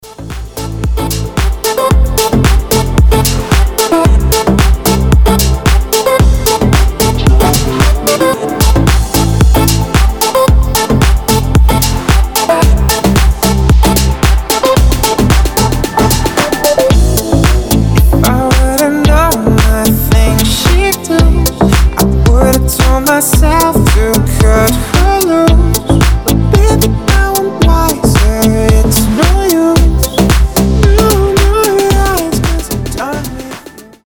мужской голос
забавные
Dance Pop
озорные
Озорной летний вайб